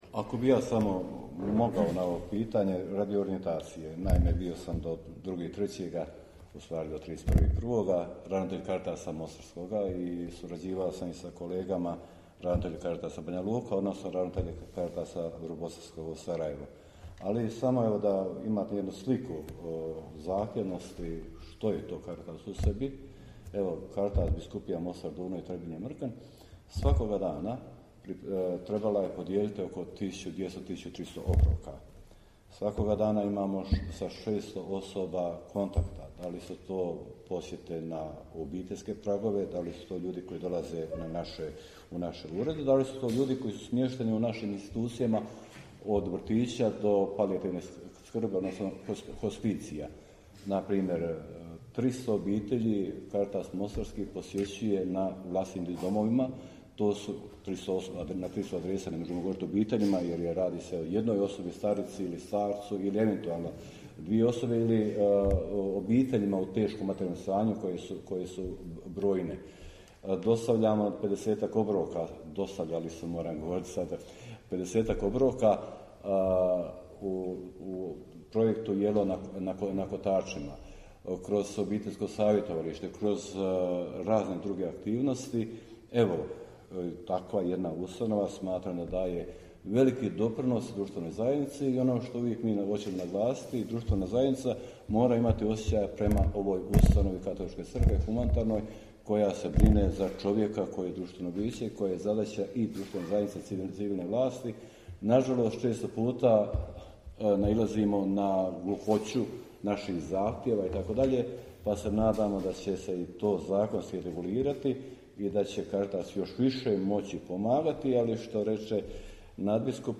Audio: Nadbiskup Vukšić i biskup Majić na konferenciji za novinstvo govorili o 90. redovitom zasjedanju BK BiH